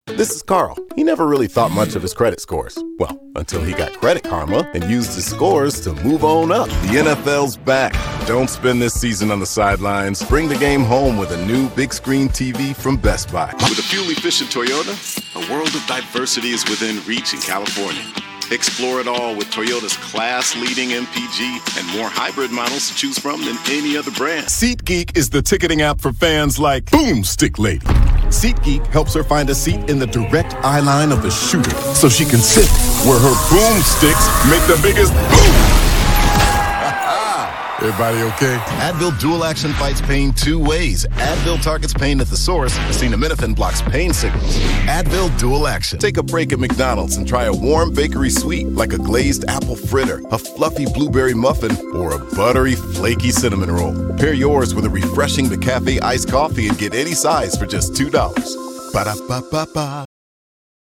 Chicago : Voiceover : Commercial : Men